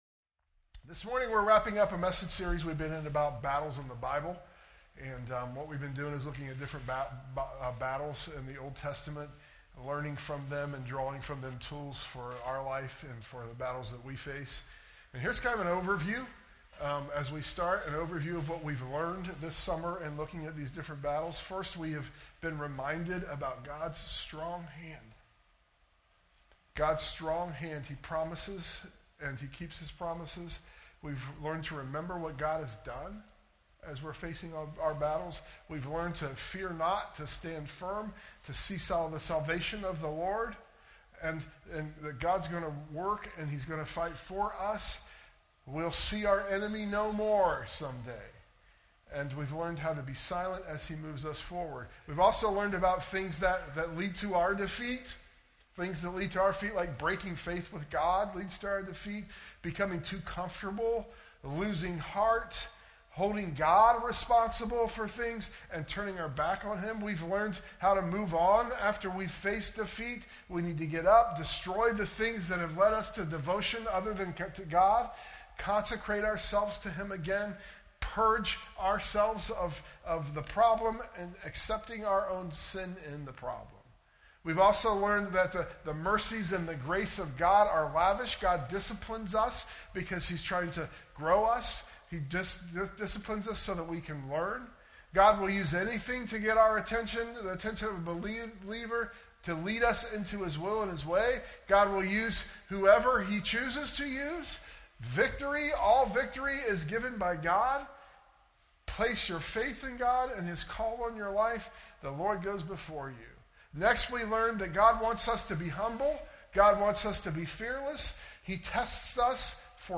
sermon_audio_mixdown_8_31_25.mp3